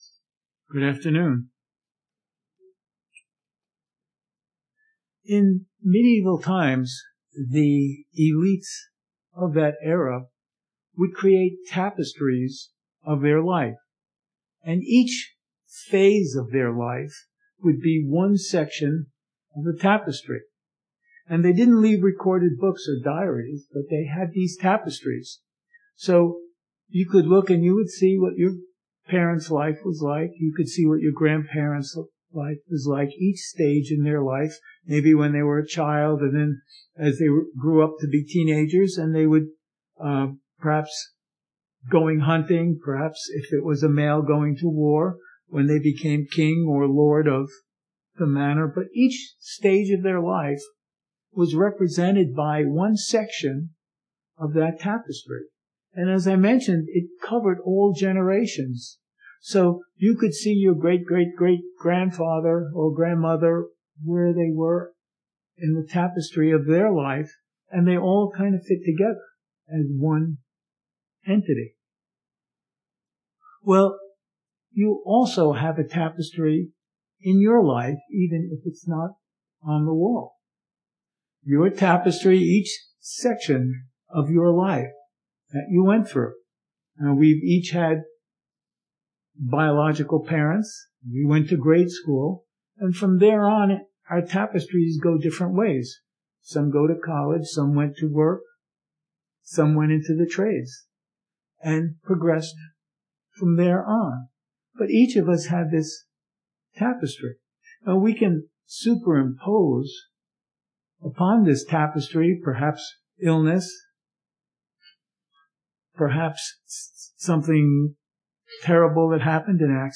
This split sermon likens our lives to tapestries that were woven in medieval times to depict a family's history through each individual's life.